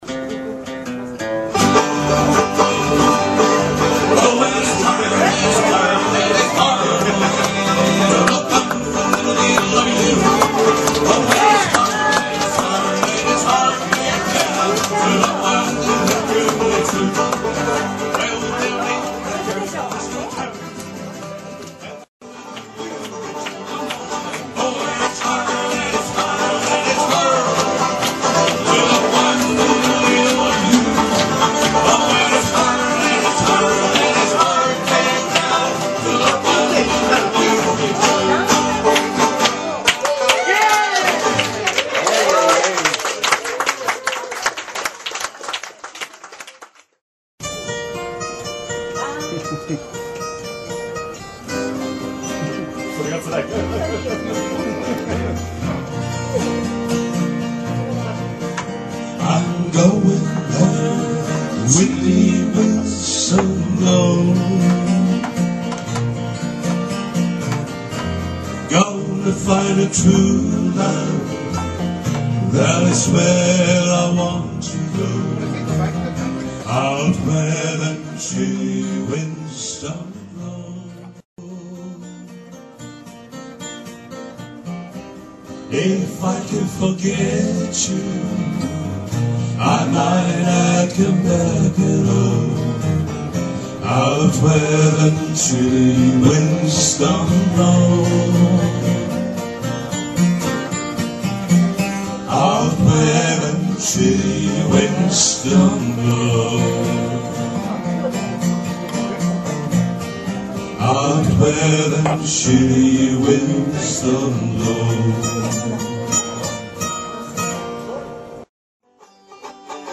仕事の帰りに手ぶらで集合、壁にずらりとならんでいるギター、バンジョーをお借りして３曲。
演奏ダイジェストは